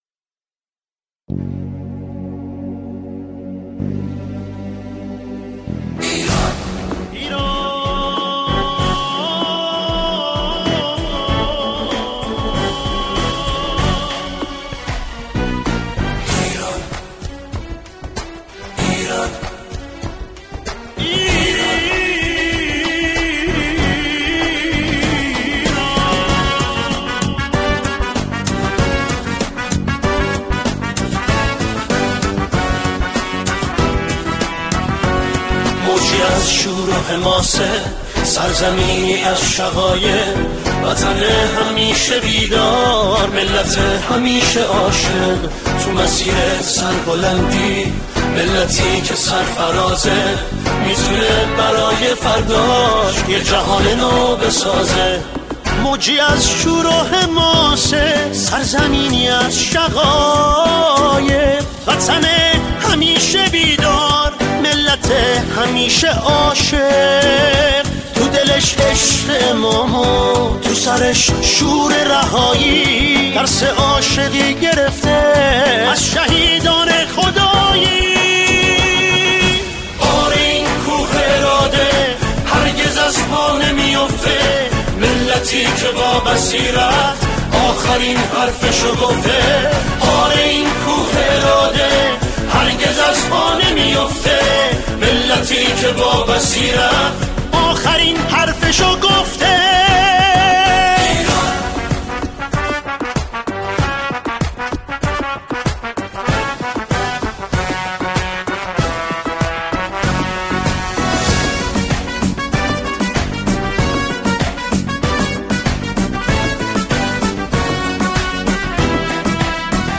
آنها در این قطعه، شعری را درباره کشور ایران همخوانی می‌کنند.